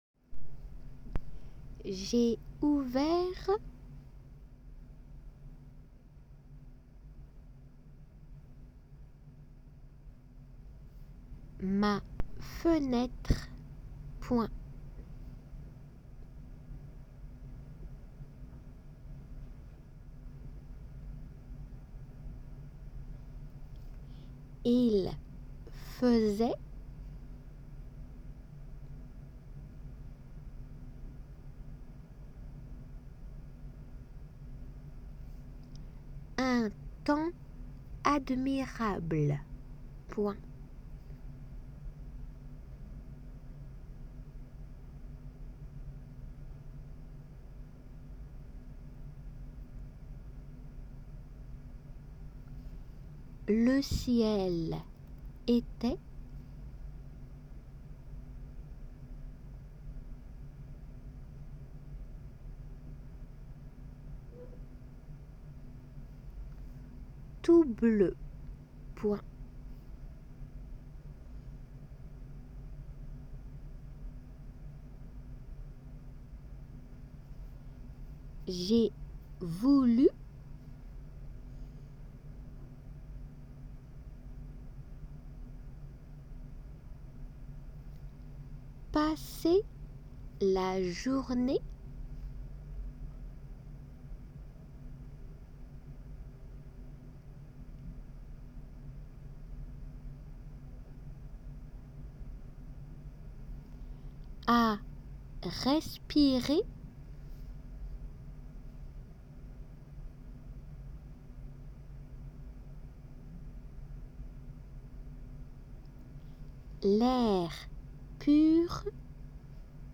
仏検2級　デイクテ　練習 7 音声